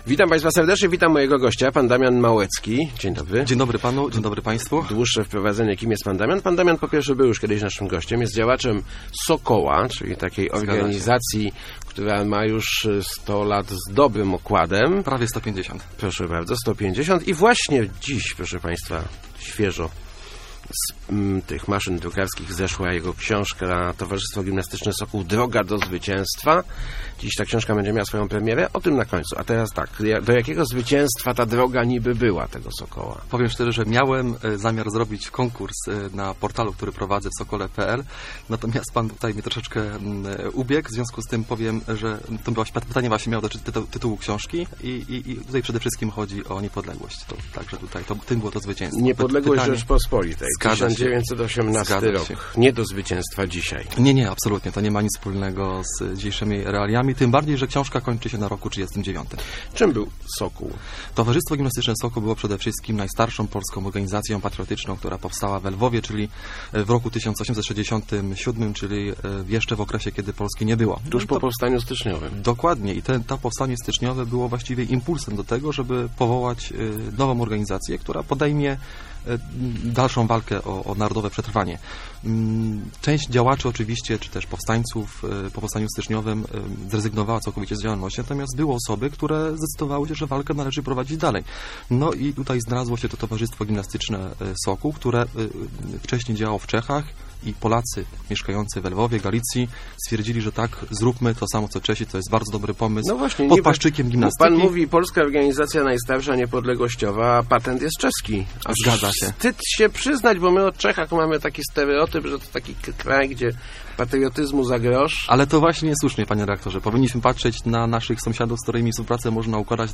Członkowie Towarzystwa Gimnastyczbegi Sokół mieli ogromny wkład w odzyskanie niepodległości, w Powstanie Wielkopolskie i powstania śląskie - mówił w Rozmowach Elki